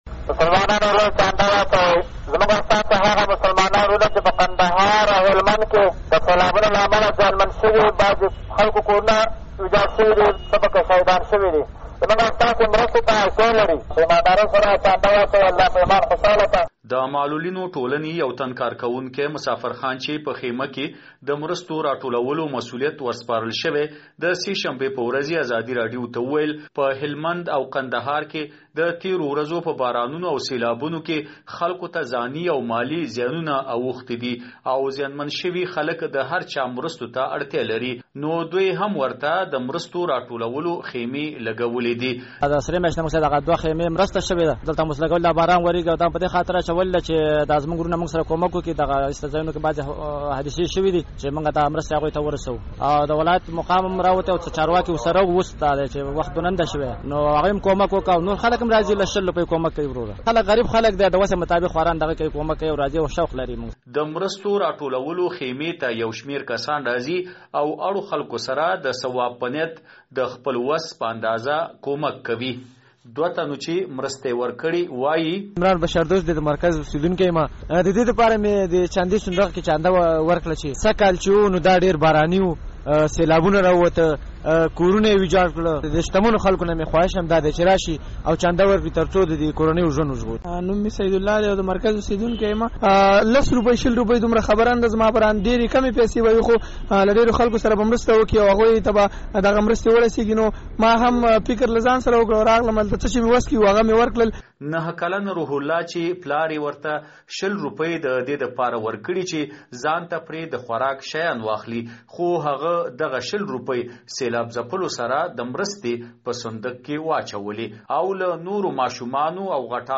د کونړ راپور